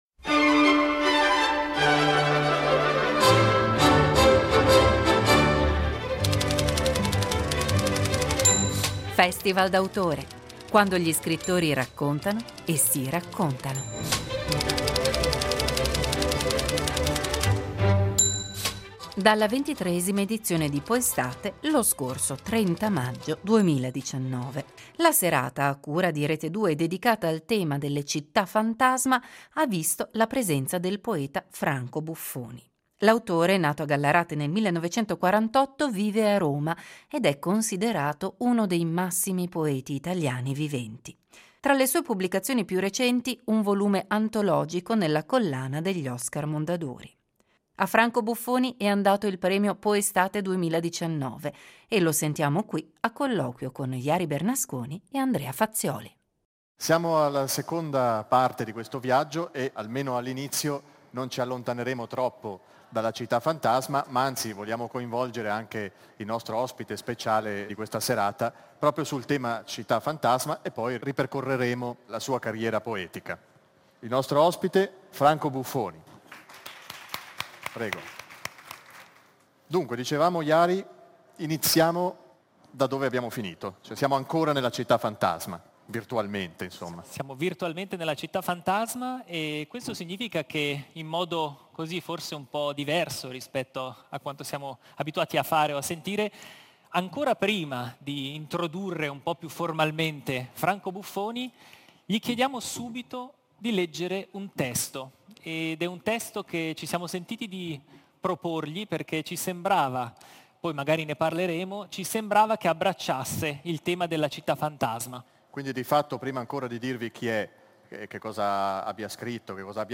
Dalla 23ma edizione di Poestate, lo scorso 30 maggio 2019.